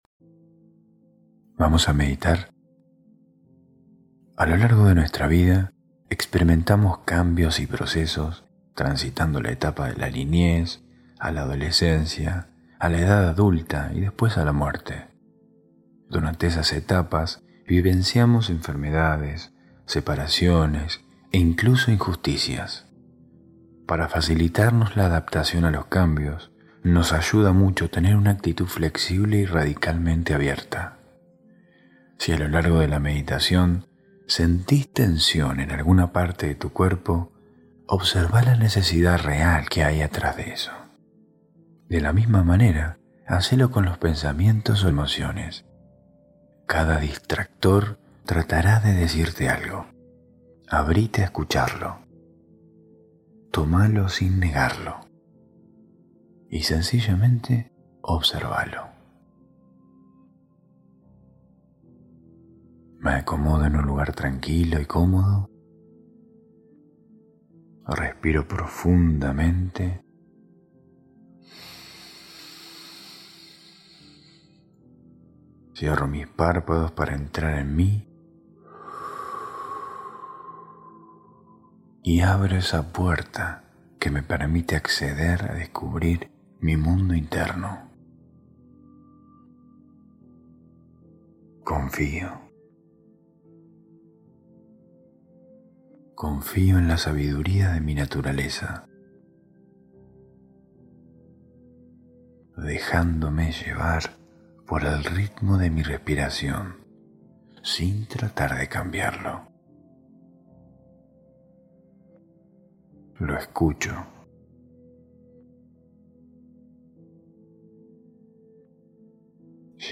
Meditación Espejo del Autoconocimiento ✨